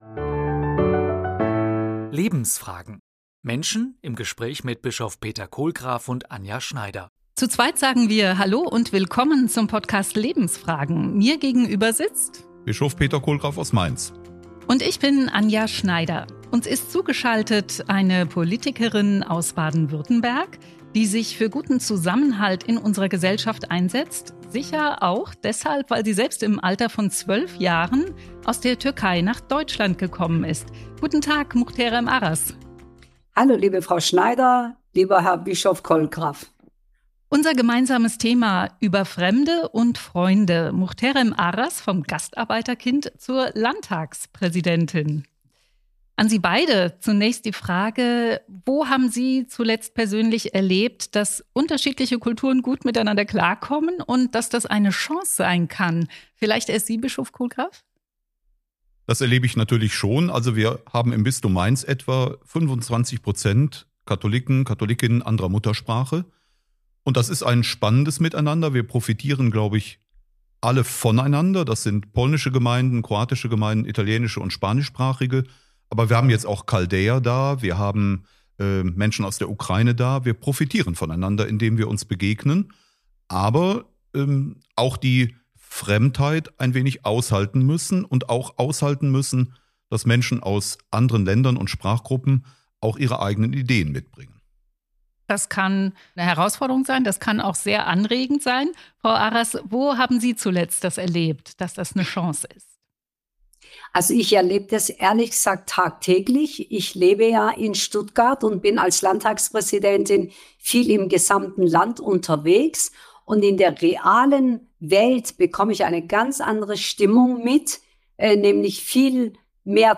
Jetzt ist Aras Gast der aktuellen Folge des Podcasts „Lebensfragen“ aus dem Bistum Mainz.